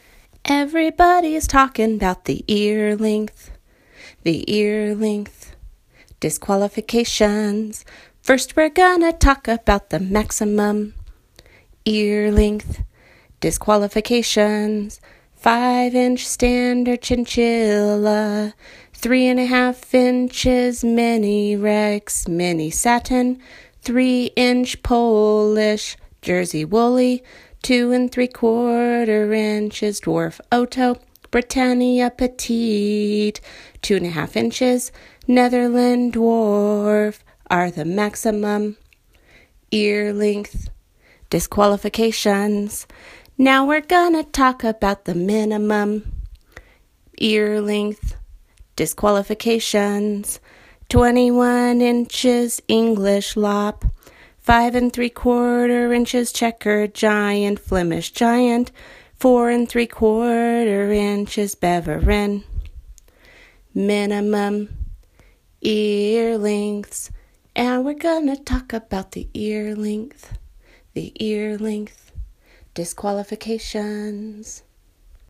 Then, I took yet another step down the rabbit hole of super-kooky and… created songs and chants.